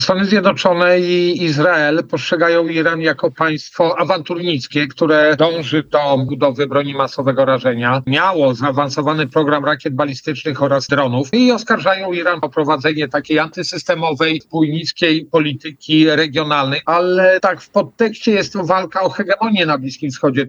Opublikowano w Aktualności, Audycje, Poranna Rozmowa Radia Centrum